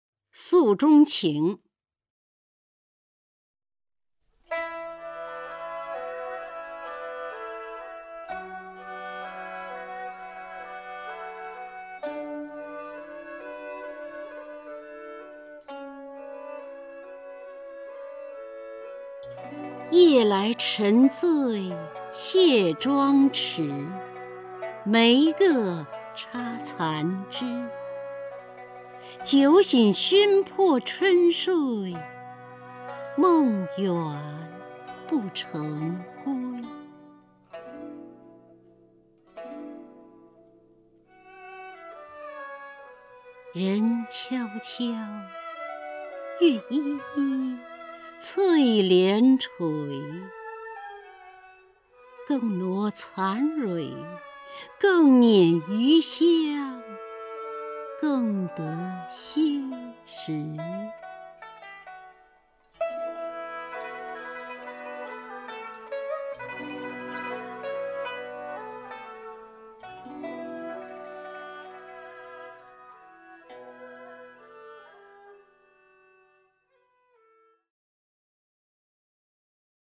首页 视听 名家朗诵欣赏 姚锡娟
姚锡娟朗诵：《诉衷情·夜来沉醉卸妆迟》(（南宋）李清照)